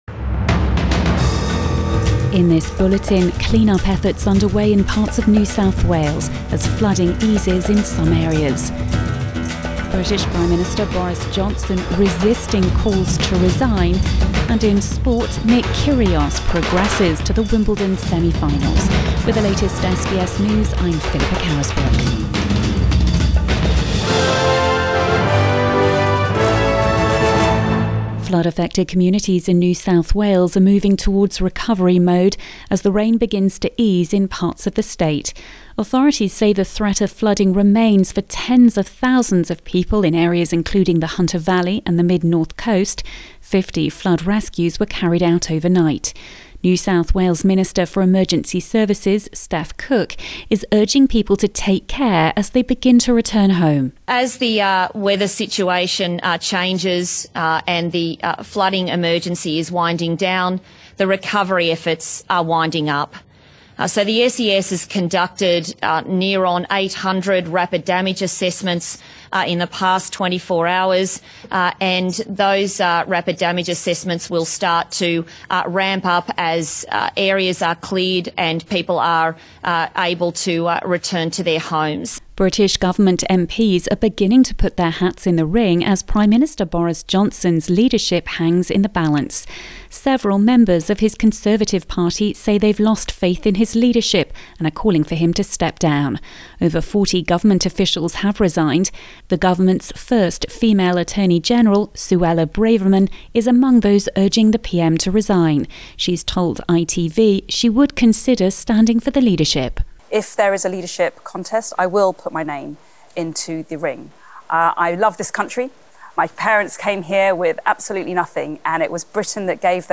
AM bulletin 7 July 2022